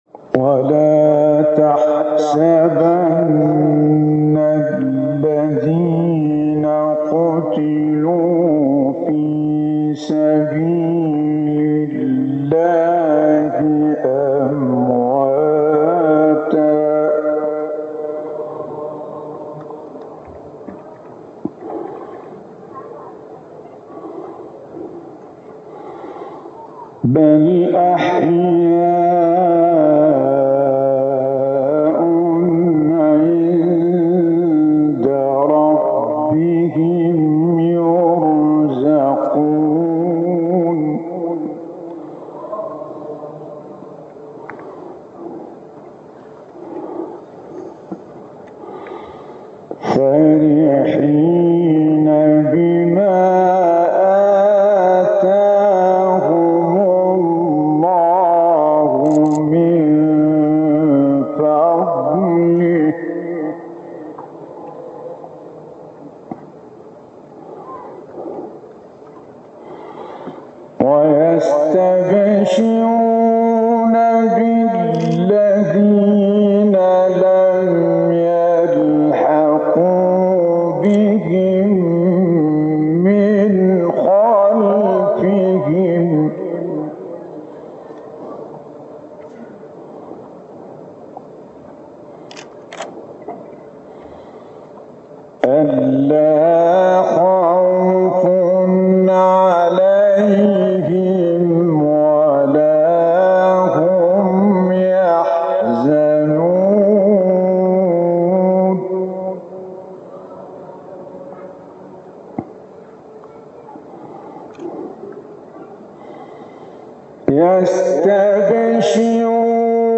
آیه 169-172 سوره آل عمران استاد متولی عبدالعال | نغمات قرآن | دانلود تلاوت قرآن